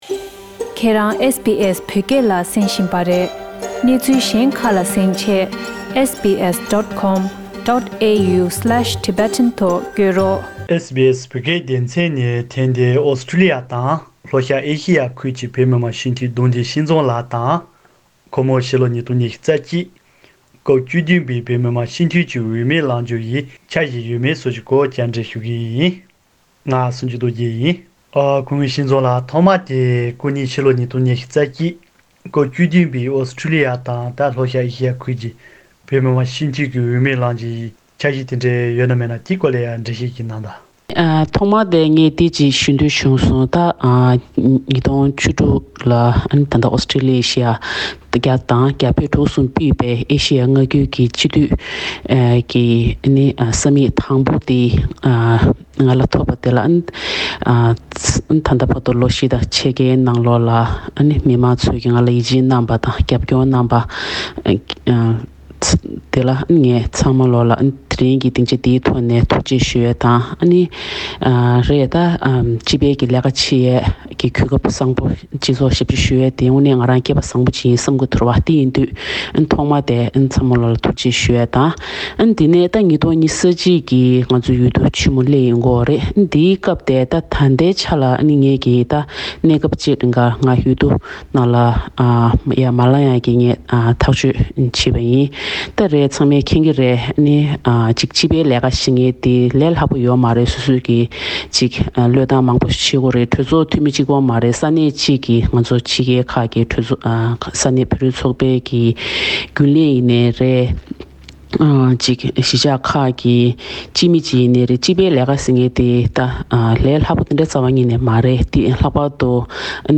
ཨོ་སི་ཊོ་ལི་ཡ་དང་ལྷོ་ཤར་ཨེ་ཤེ་ཡའི་ཁུལ་གྱི་བོད་མི་མང་སྤྱི་འཐུས་གདོང་འདུས་སྐྱིད་འཛོམས་ལགས་དང་། ཁོ་མོ་ཕྱི་ལོ་ ༢༠༢༡ སྐབས་བཅུ་བདུན་པའི་བོད་མི་མང་སྤྱི་འཐུས་ཀྱི་འོས་མིར་ལང་རྒྱུའི་འཆར་གཞི་ཡོད་མེད་སོགས་ཀྱི་སྐོར་བཅར་འདྲི་ཞུས་པ།